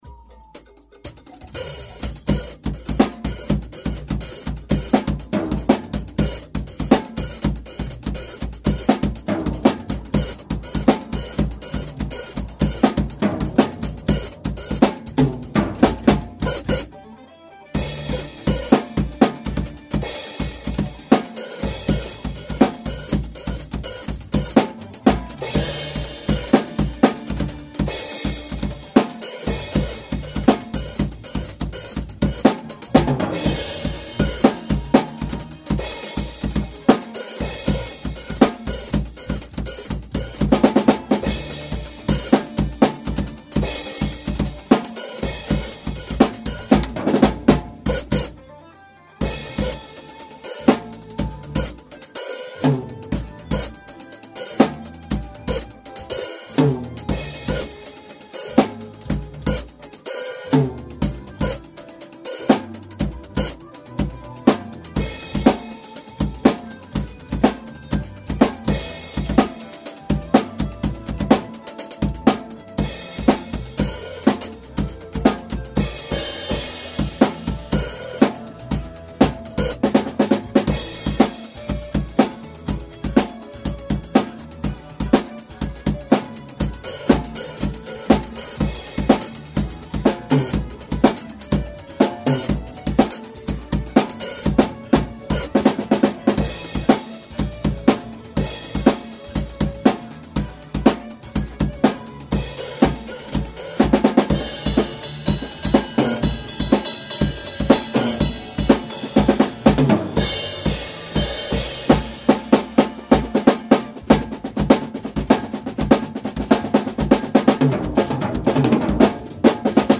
드럼